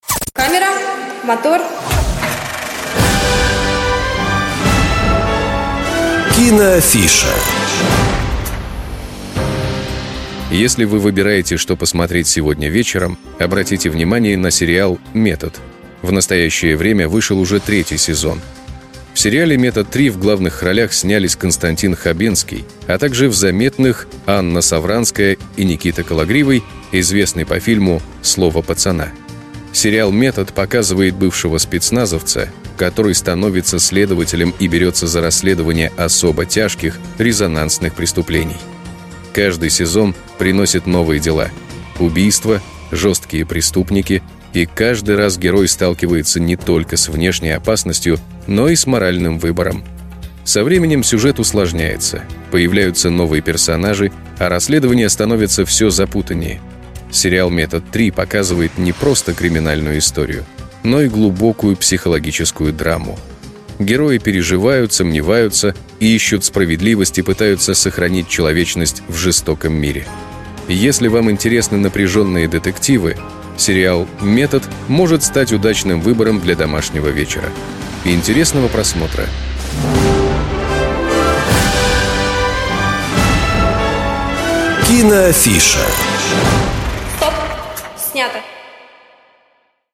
Небольшие аудиорассказы о фильмах и сериалах, которые помогут определиться с выбором на вечер.